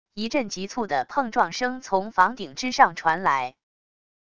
一阵急促的碰撞声从房顶之上传来wav音频